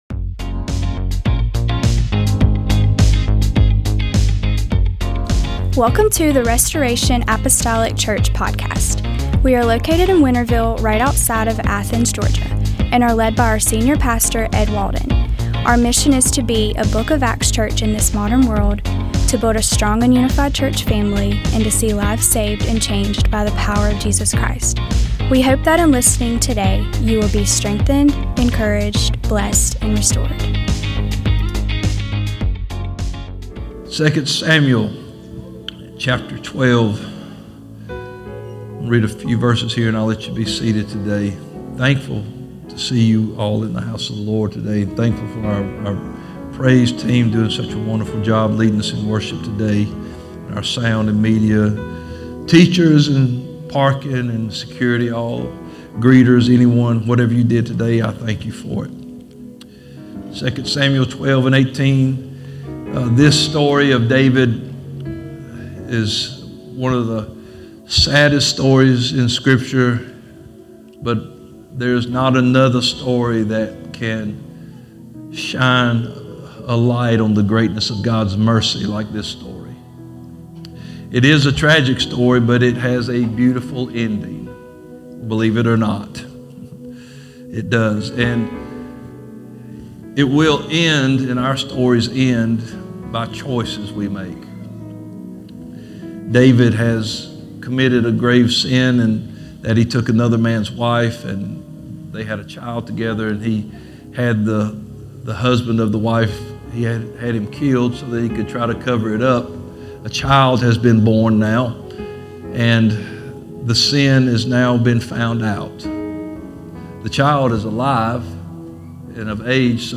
Sunday Service- 08/17/2025
In this sermon